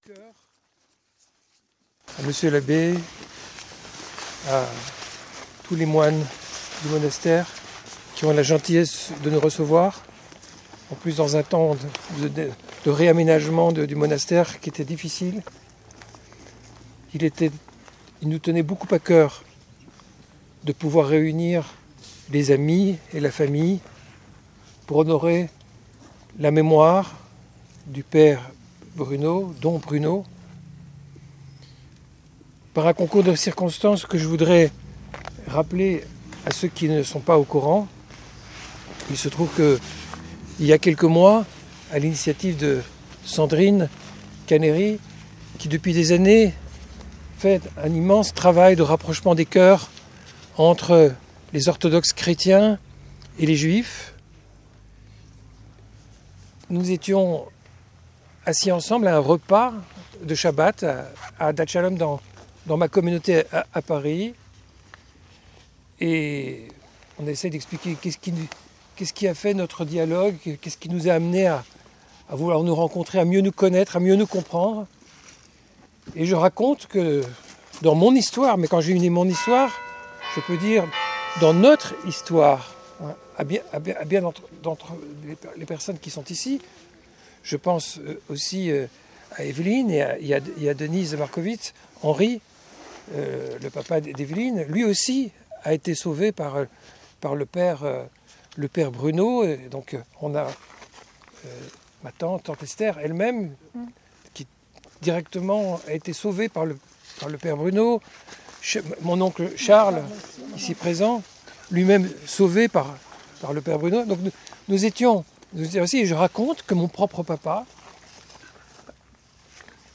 Prières juives sur la tombe de Père Bruno et hommage rendu par des témoins : enfants cachés, sauvés par le père Bruno.
à 25'45" Prière El Male Rahamim